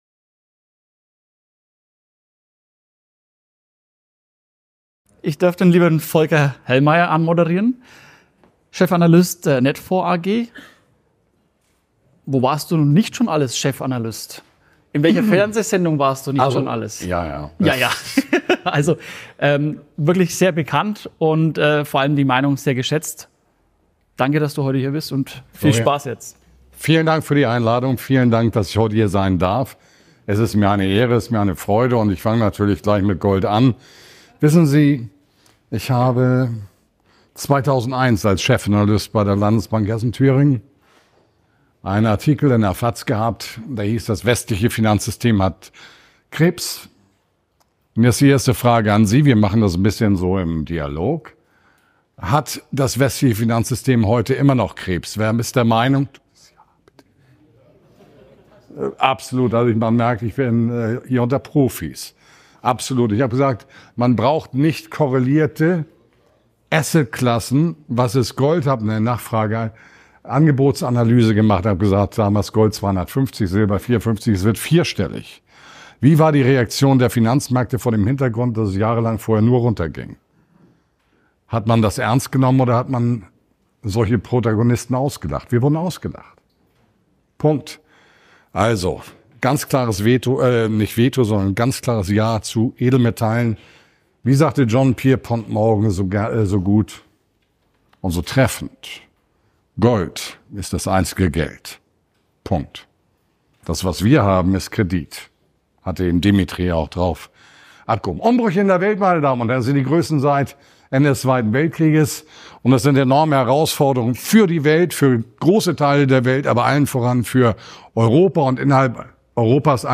Ein aufrüttelnder Vortrag über die größten wirtschaftlichen Herausforderungen unserer Zeit und mögliche Lösungswege